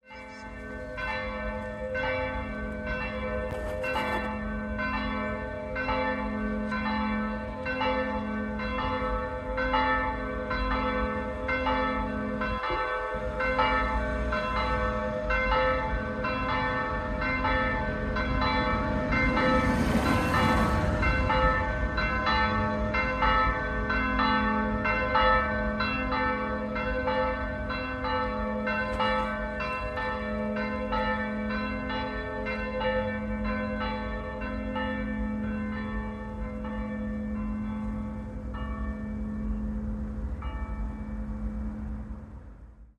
Die Glocken unserer Herz-Jesu-Kirche – Pfarrei Heilige Elisabeth
Glockenlaeuten-Herz-Jesu-Kirche.mp3